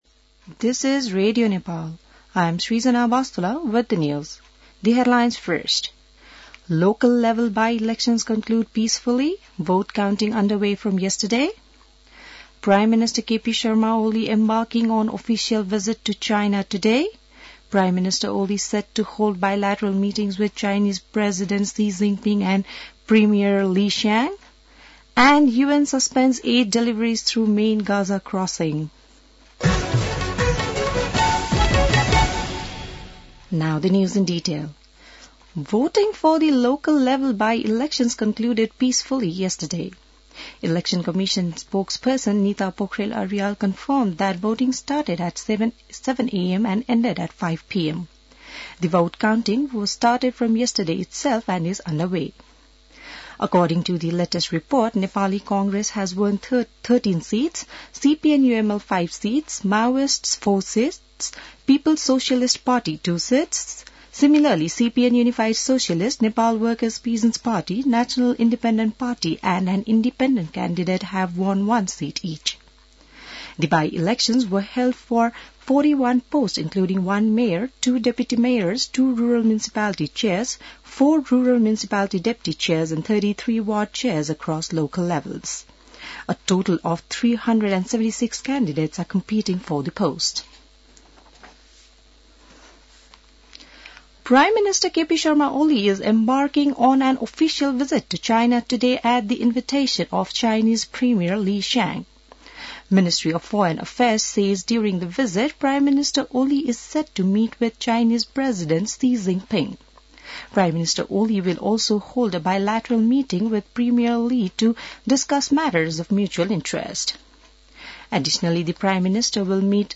An online outlet of Nepal's national radio broadcaster
बिहान ८ बजेको अङ्ग्रेजी समाचार : १८ मंसिर , २०८१